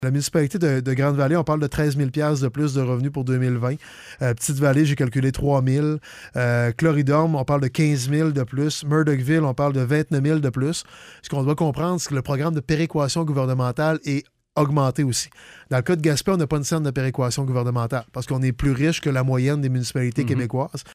Le préfet de la MRC de La Côte-de-Gaspé donne aussi les montants qui seront alloués en 2020 pour les autres municipalités du secteur: